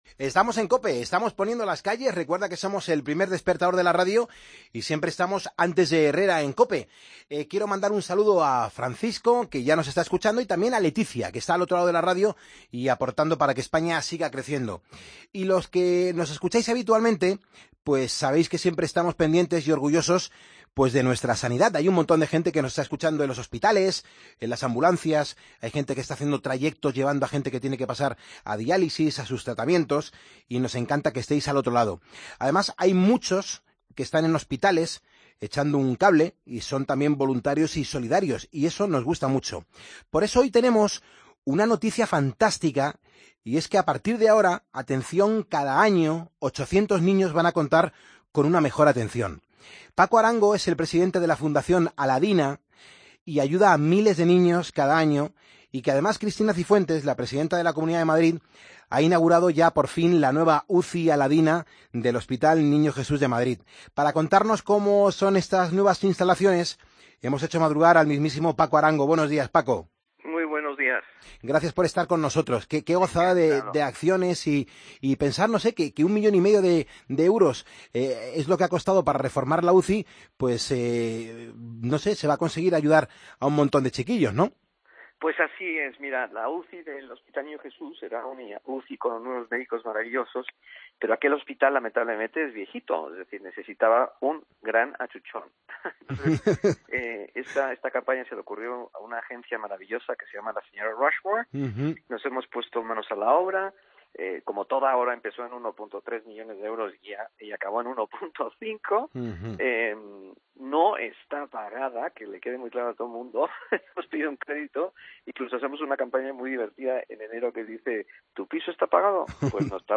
AUDIO: Charlamos con Paco Arango, presidente de la Fundación Aladina.